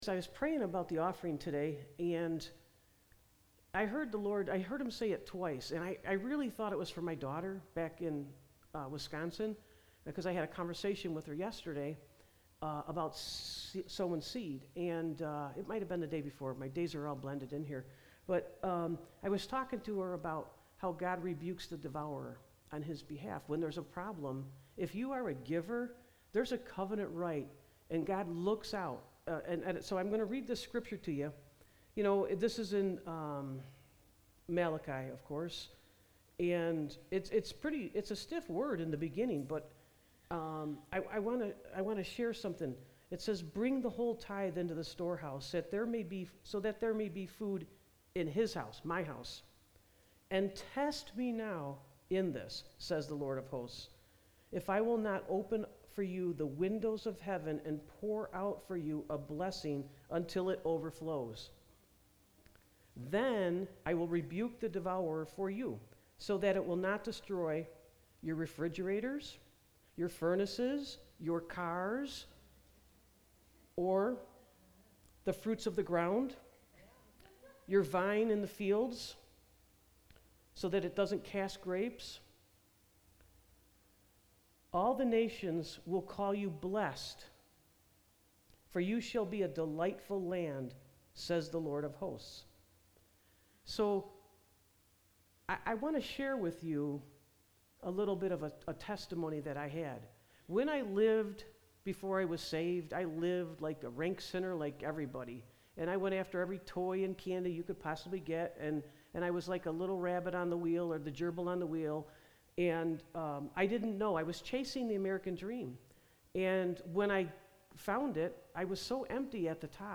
Sermons | Spirit Of God Ministries WorldWide